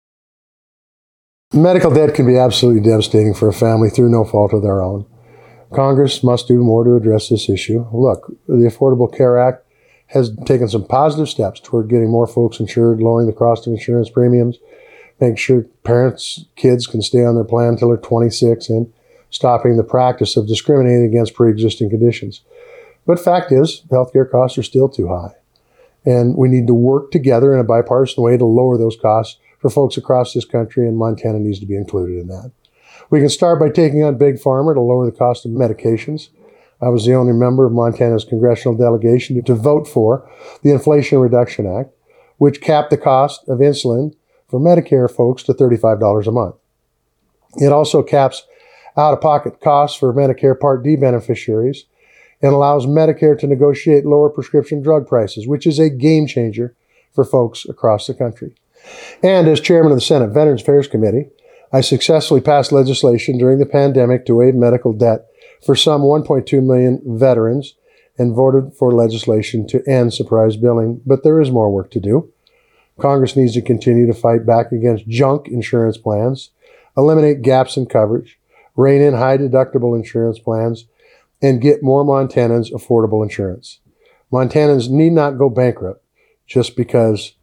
The candidates' responses were aired on Voices of Montana, October 9-11, 2024.